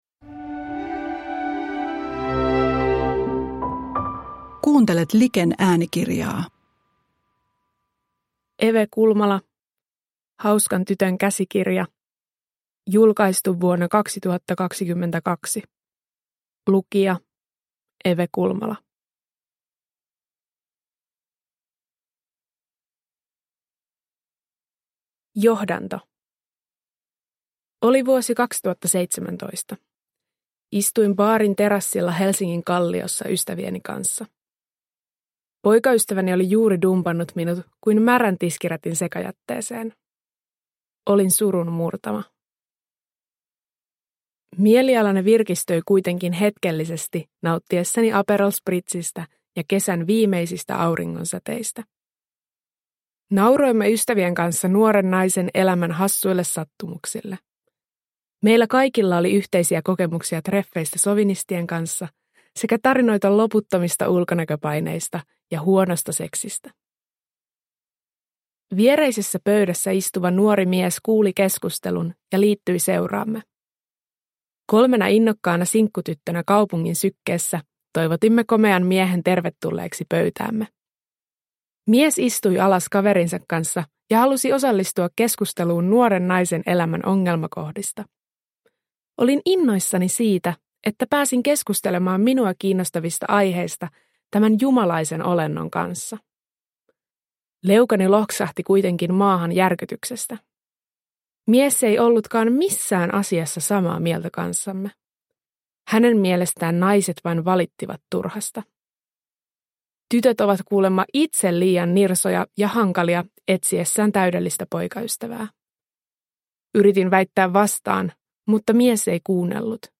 Hauskan tytön käsikirja – Ljudbok